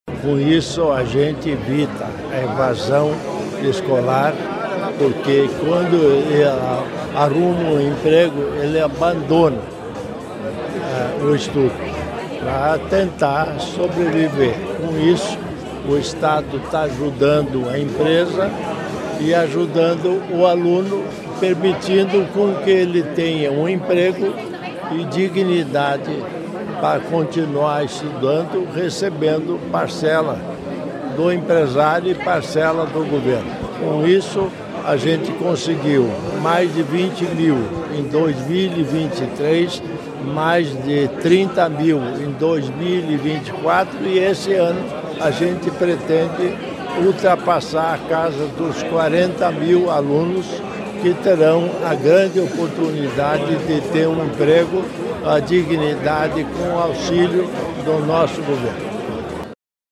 Sonora do vice-governador Darci Piana sobre o lançamento da iniciativa Conexão Empregabilidade